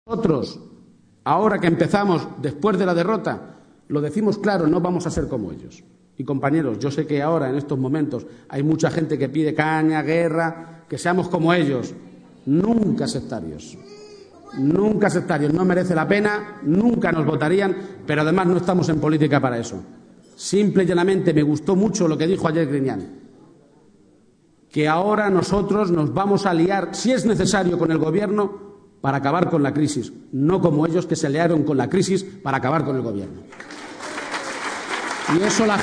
Intervención García-Page